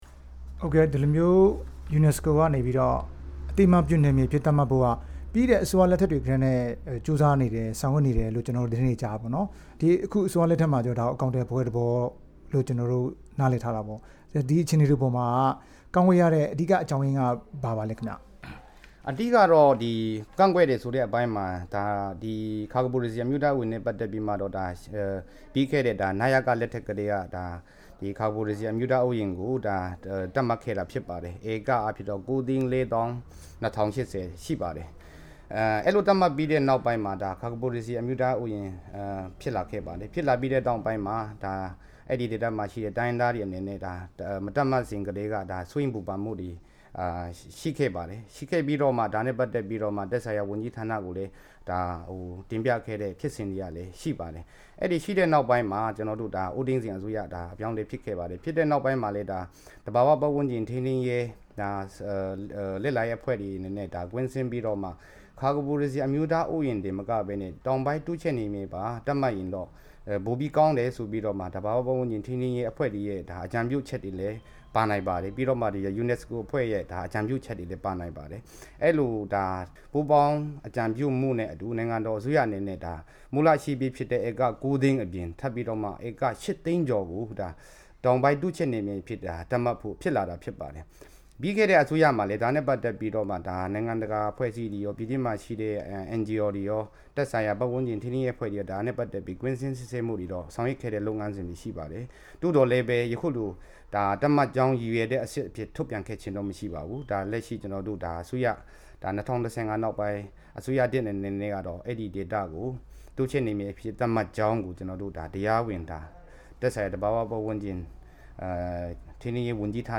ဒီကနေ့တွေ့ဆုံမေးမြန်းထားပါတယ်။